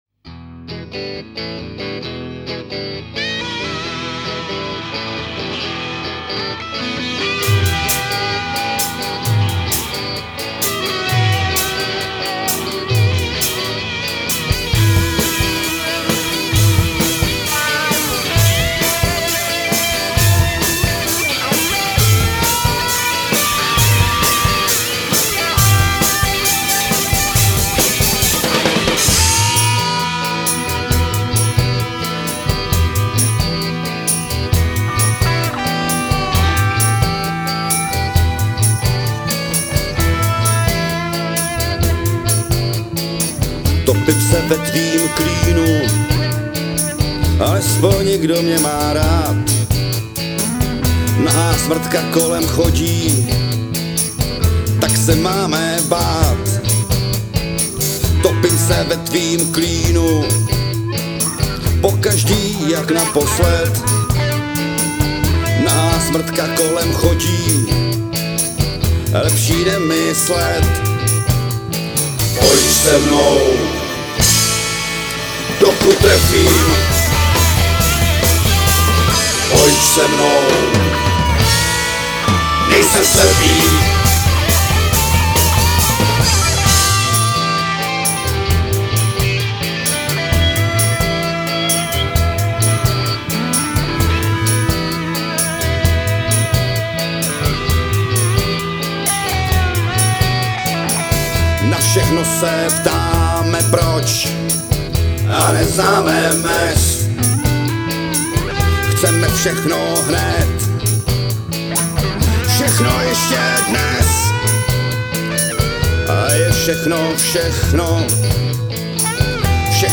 housle, zpěv
kytara, havajka, pila, zpěv
baskytary
bubny
saxofon